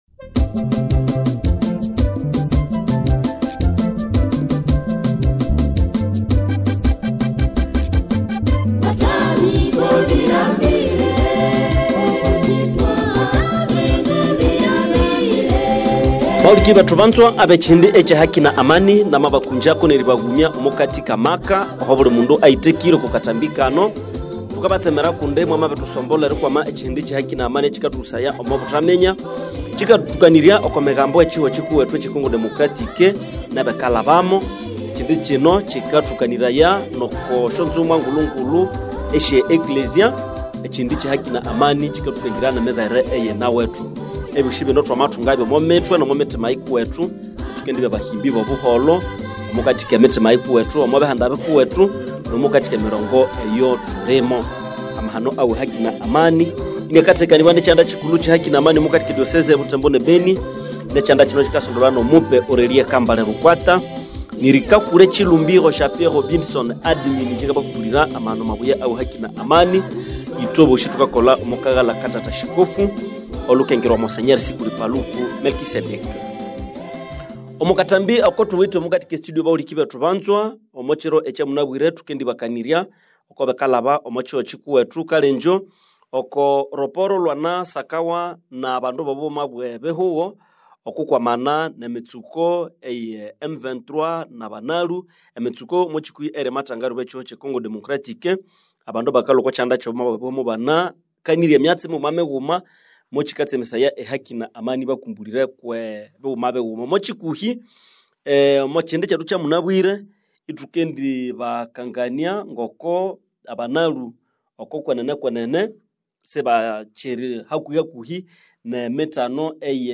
Émission Radio Écoutez l'émission ci-dessous